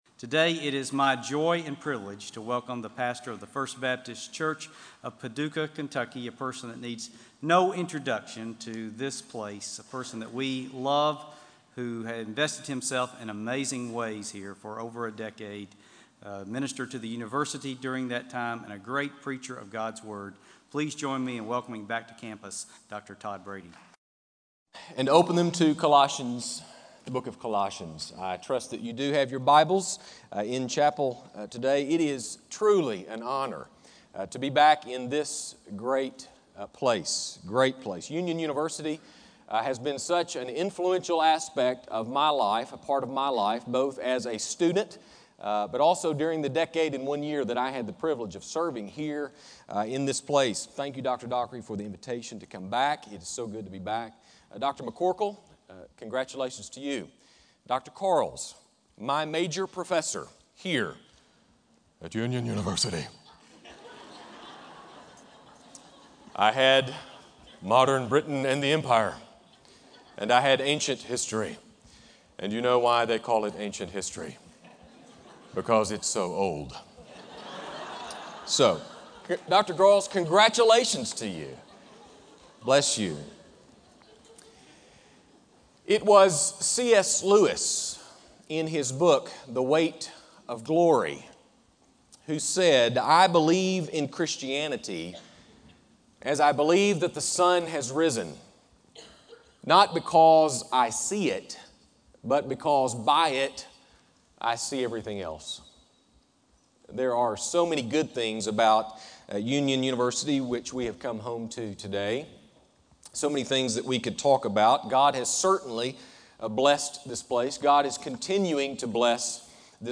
Homecoming Chapel